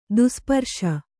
♪ dusparśa